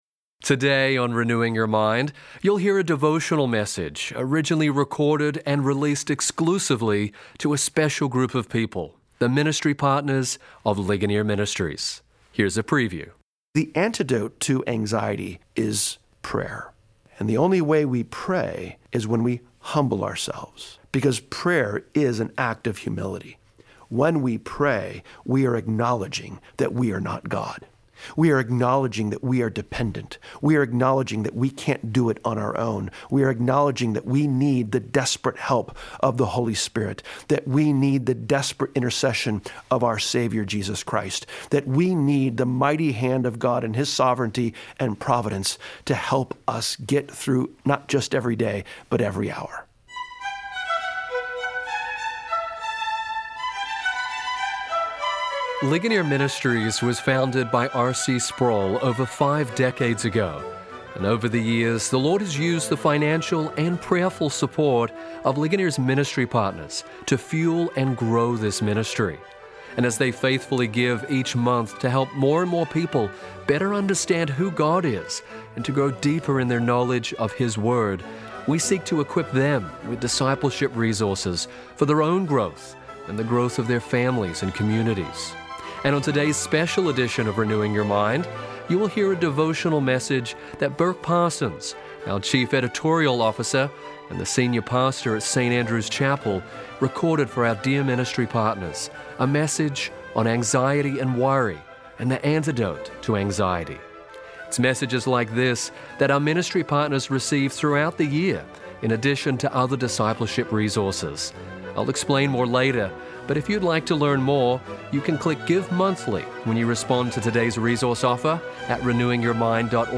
devotional message